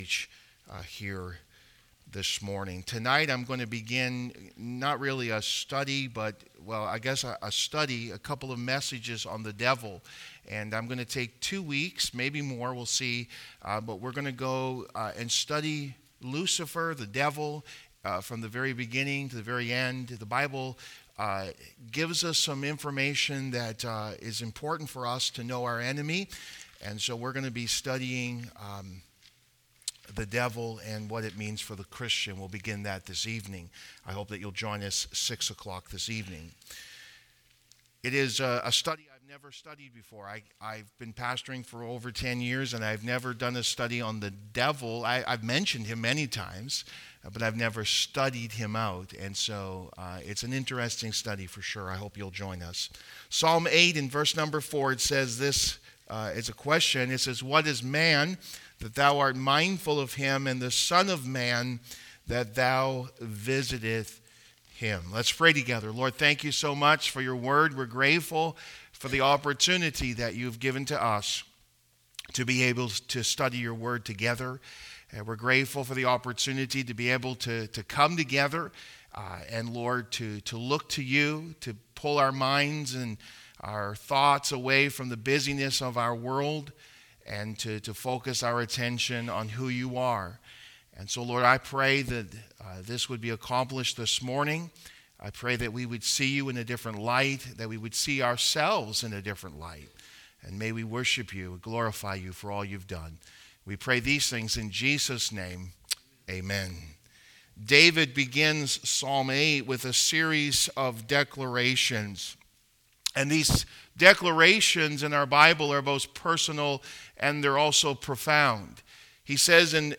Why Would God Care for Us | Sermons
nov16-25-sunday-morning.mp3